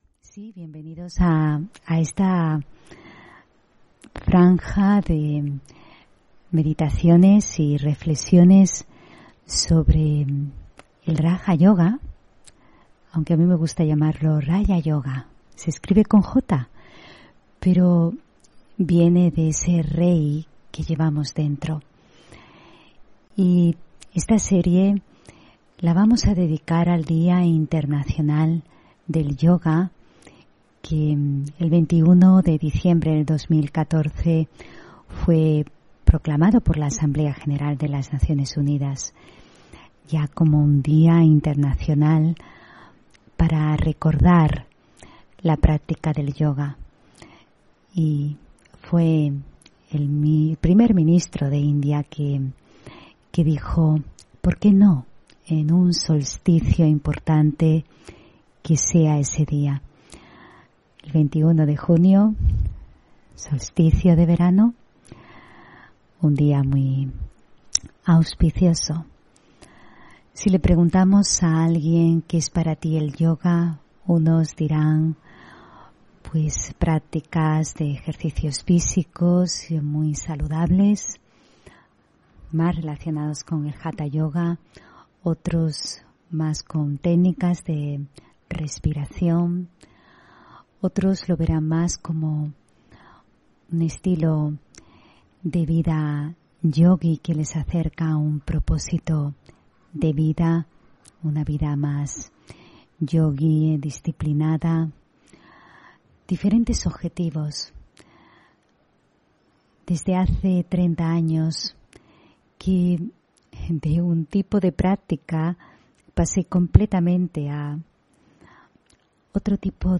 Meditación y conferencia: Raja Yoga para vivir más consciente (15 Junio 2022)